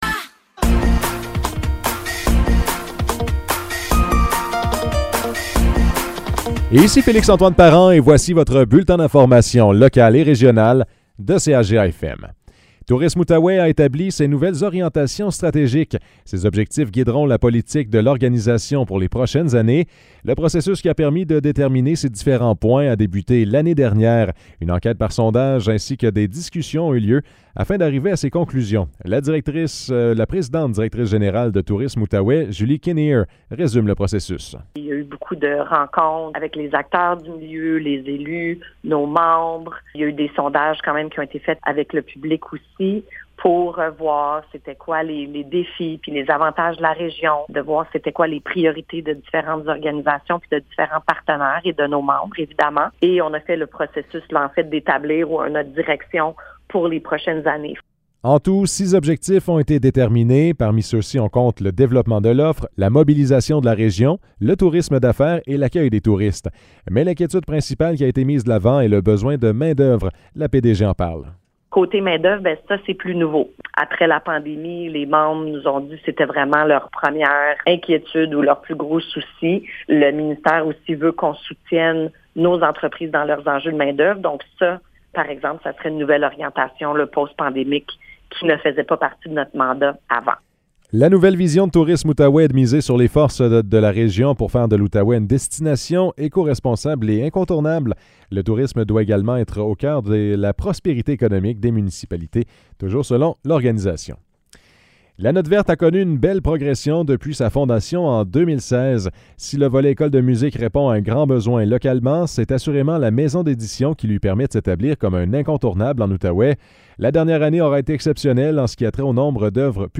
Nouvelles locales - 21 juin 2023 - 15 h